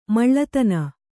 ♪ maḷḷatana